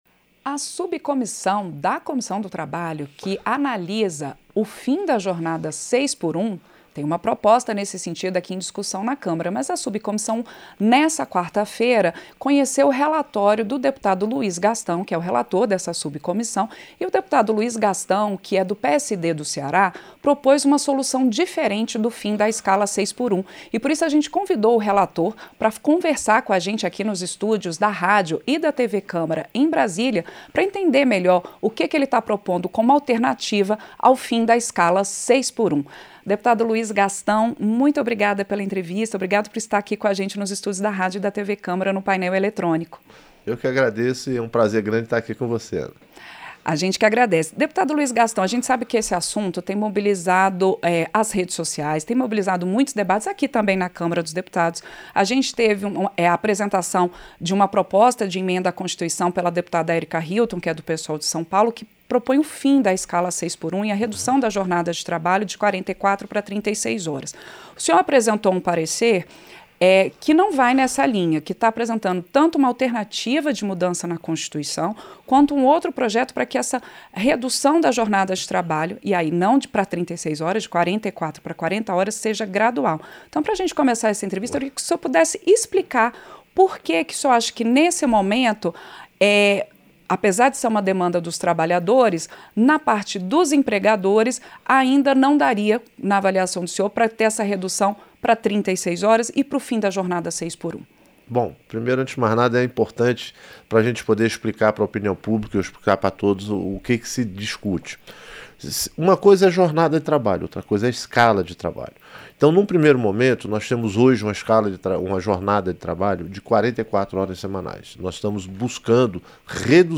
Entrevista - Dep. Luiz Gastão (PSD-CE)